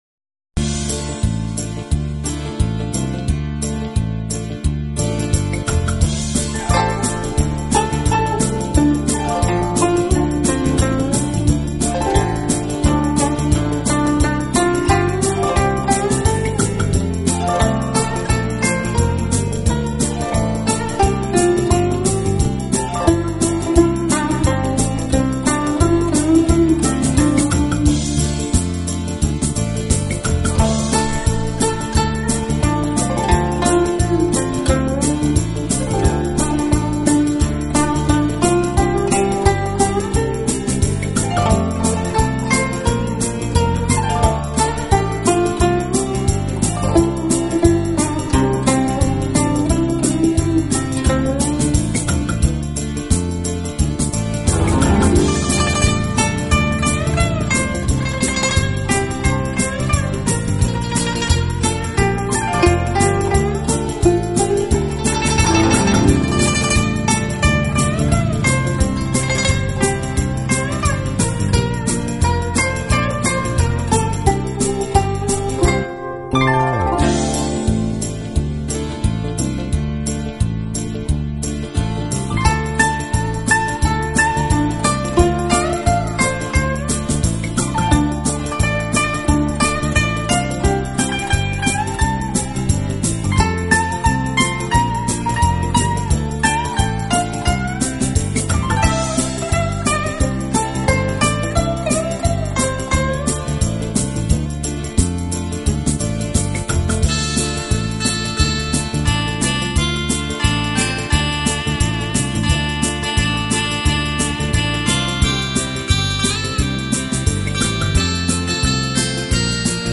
类别: 轻音乐